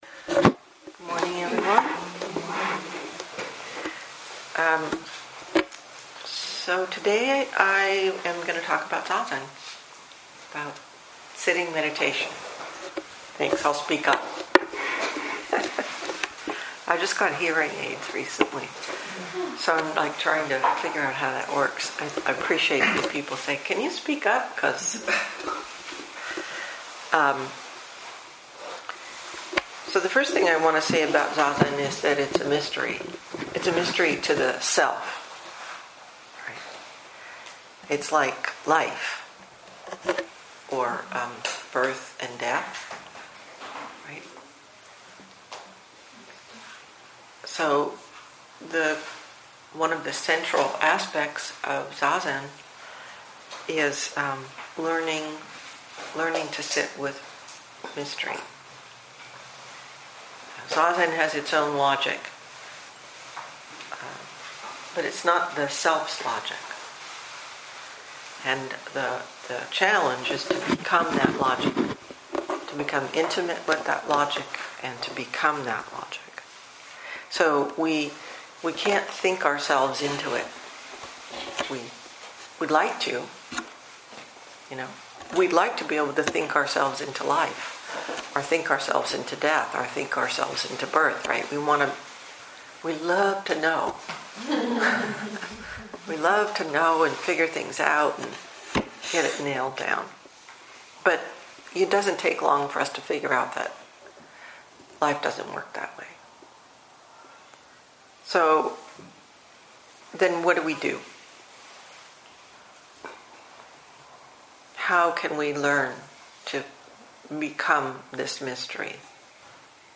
2019 in Dharma Talks